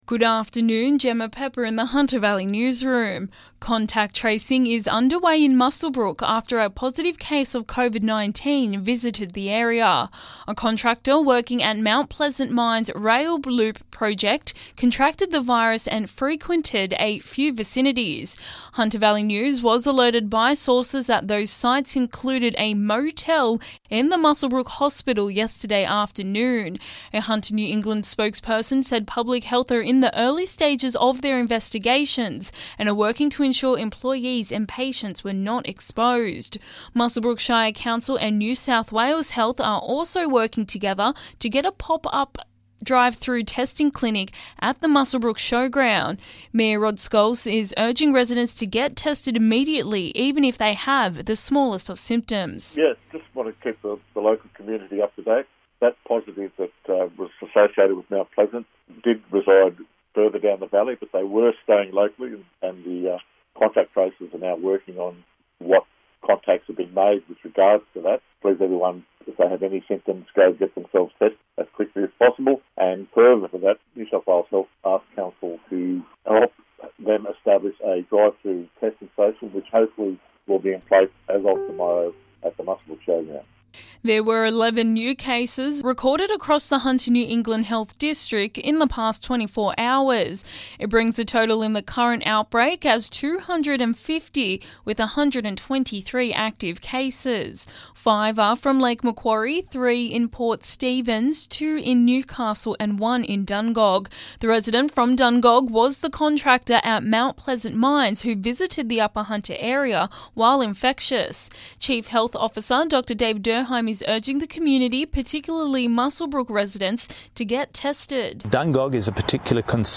Listen: Hunter Local News Headlines 08/09/2021